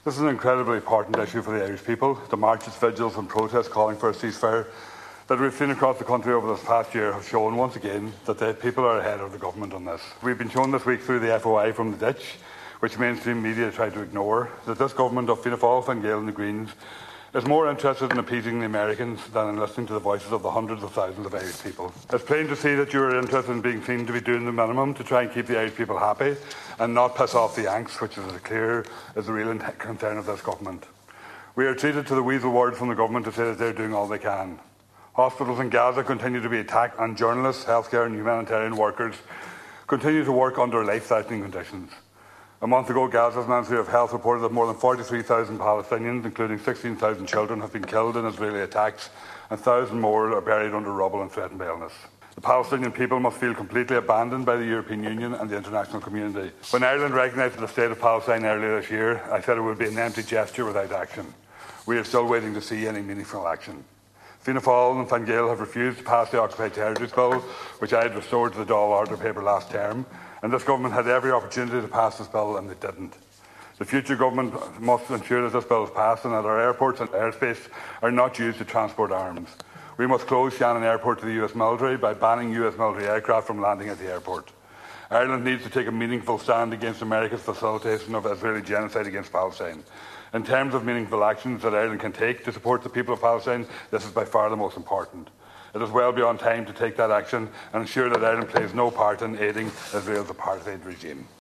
Speaking on the motion, Donegal Deputy Thomas Pringle called for meaningful actions against genocide, not what he says are ‘weasel words’.